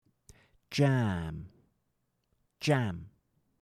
jam (fruit) – /dʒæːm/ vs. jam (music) – /dʒæm/